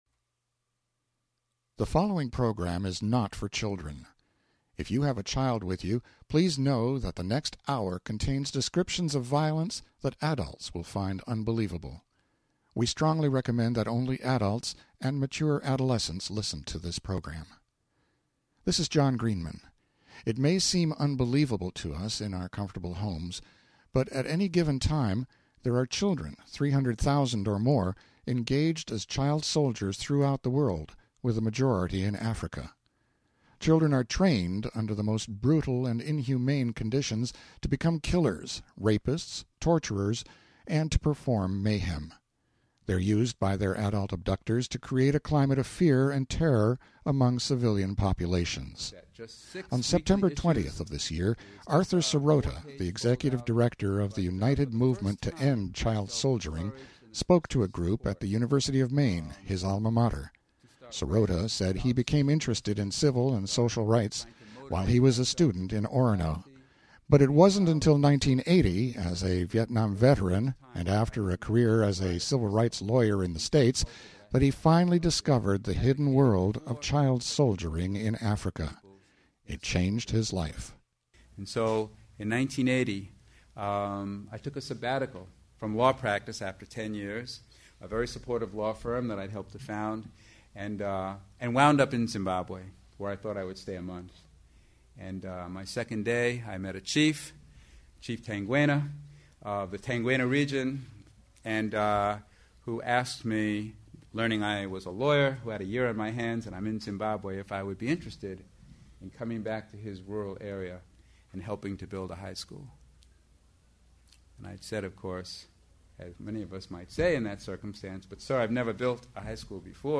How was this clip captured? Recorded Sept. 20th, 2007 at UMaine Most everyone has heard that children are terrorized into becoming fighters in several places around the world.